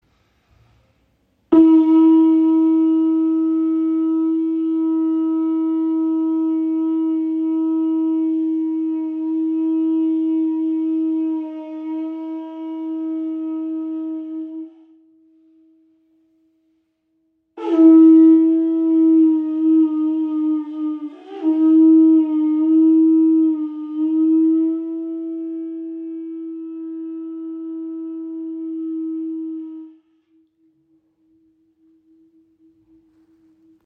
Kuhhorn, Signalhorn
Klangbeispiel
Dieses authentische Stück verkörpert die tiefe Verbundenheit mit der Vergangenheit und bringt einen kräftigen, durchdringenden und tragenden Klang hervor.
Natürliches Kuhhorn mit kräftigem Klang
Kräftiger Klang der weit herum hörbar ist